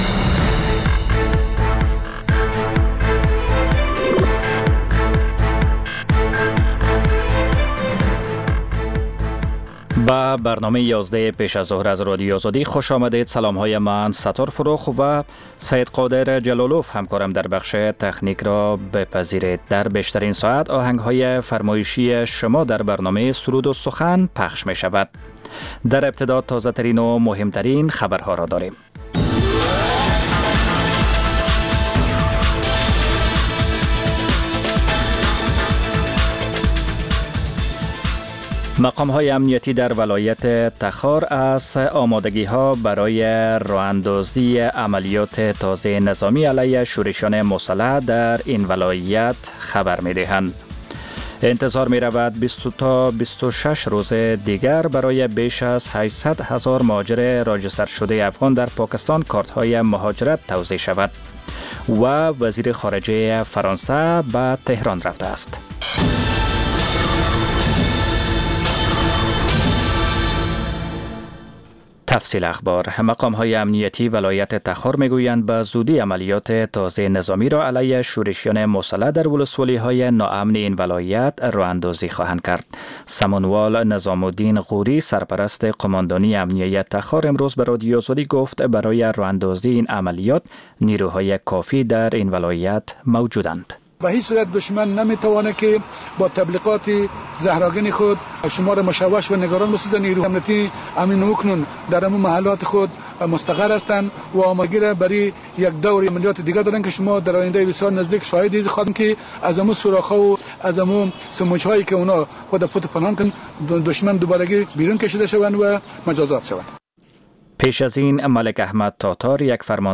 خبر ها و گزارش‌ها، سرود و سخن